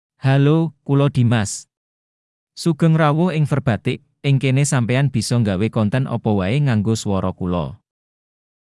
Dimas — Male Javanese AI voice
Dimas is a male AI voice for Javanese (Latin, Indonesia).
Voice sample
Listen to Dimas's male Javanese voice.
Dimas delivers clear pronunciation with authentic Latin, Indonesia Javanese intonation, making your content sound professionally produced.